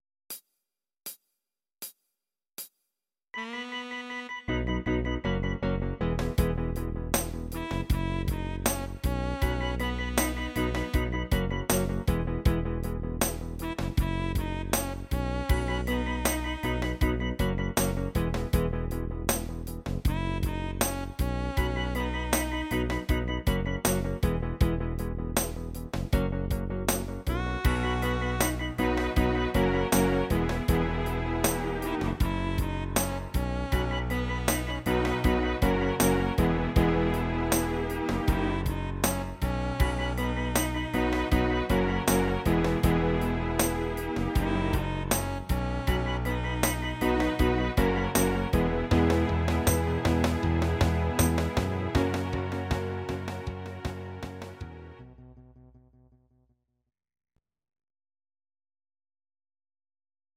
Audio Recordings based on Midi-files
Pop, Rock, Oldies, 1960s